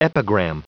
Prononciation du mot epigram en anglais (fichier audio)
Prononciation du mot : epigram